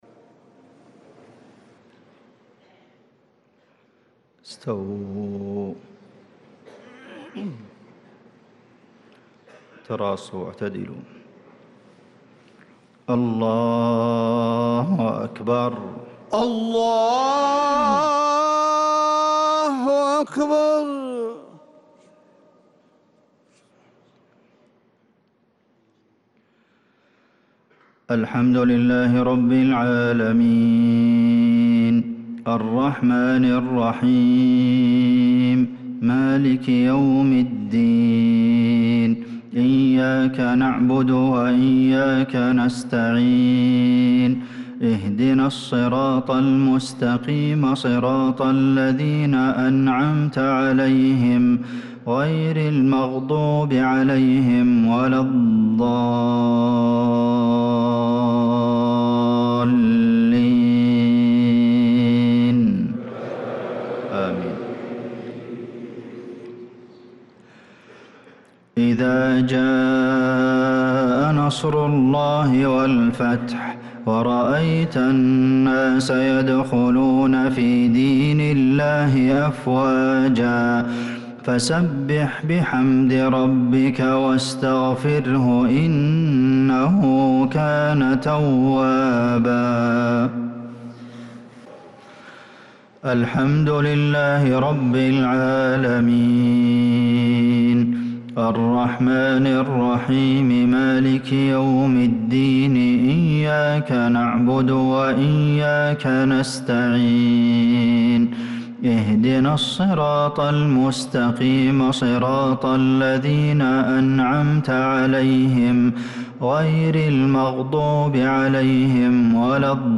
صلاة المغرب للقارئ عبدالمحسن القاسم 8 شوال 1445 هـ
تِلَاوَات الْحَرَمَيْن .